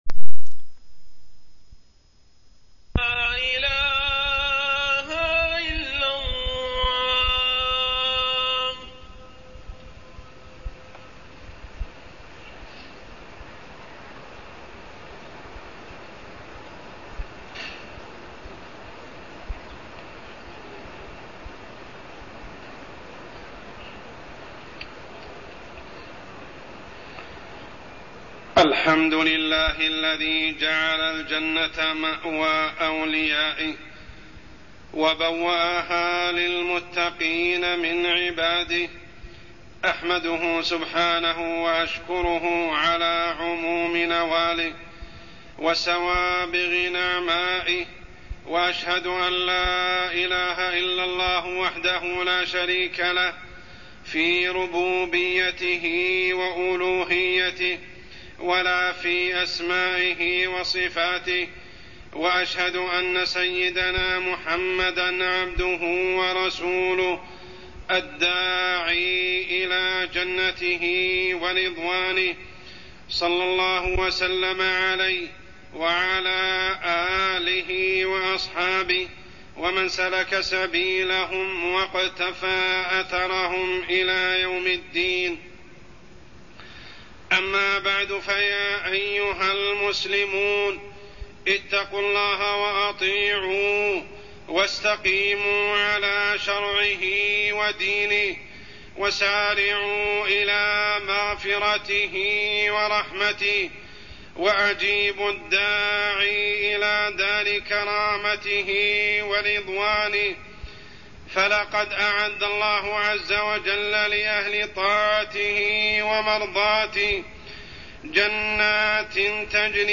تاريخ النشر ٢٣ محرم ١٤٢١ هـ المكان: المسجد الحرام الشيخ: عمر السبيل عمر السبيل نعيم الجنة